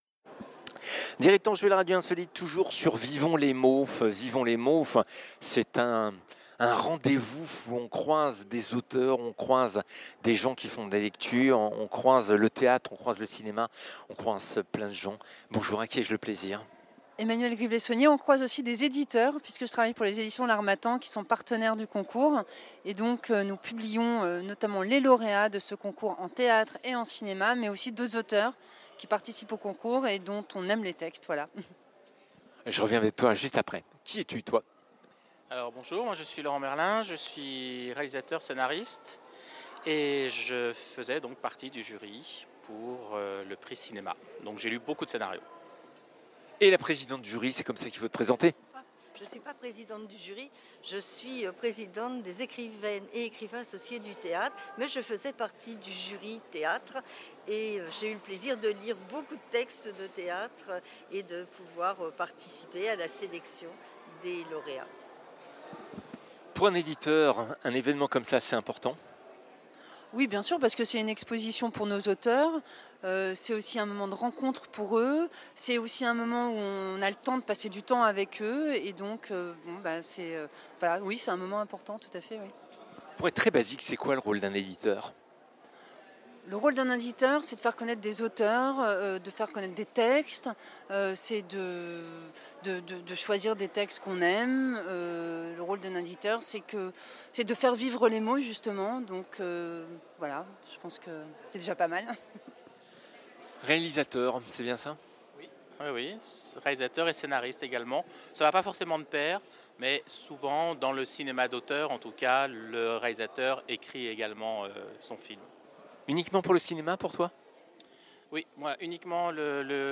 Salon Vivons les Mots Cannes Mandelieu, un réalisateur de cinéma, une auteure et une editrice